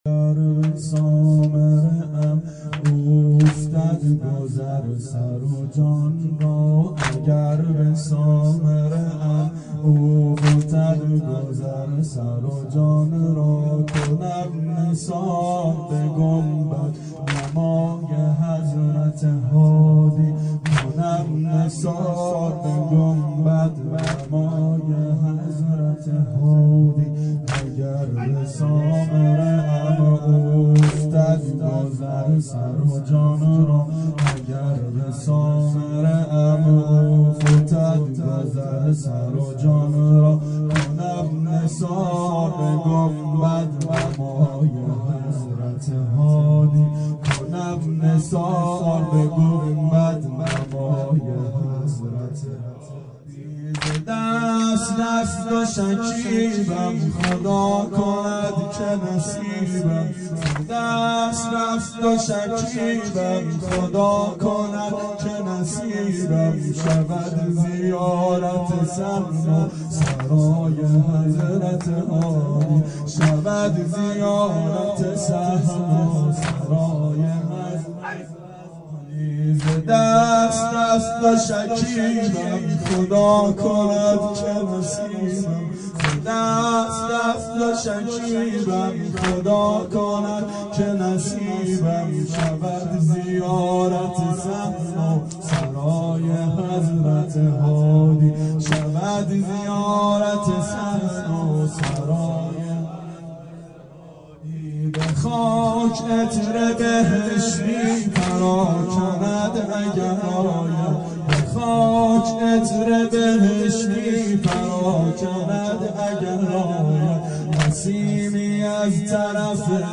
• شب شهادت امام هادی علیه السلام 92 محفل شیفتگان حضرت رقیه سلام الله علیها